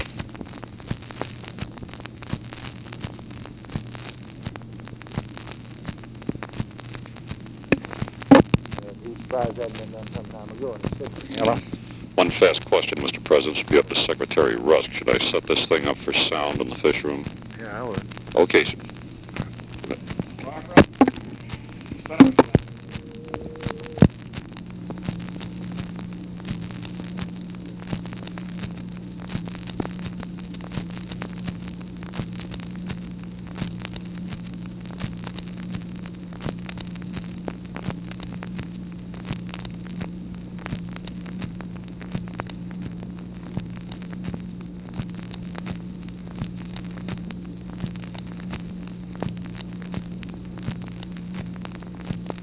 Conversation with GEORGE REEDY, April 20, 1964
Secret White House Tapes